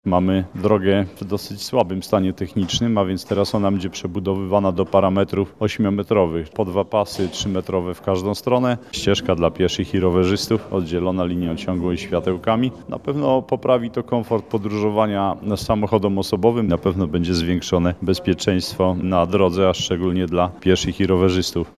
– Dzięki tej dotacji, także powiat ostrołęcki rozbuduje kilka kilometrów dróg – mówi starosta ostrołęcki Stanisław Kubeł.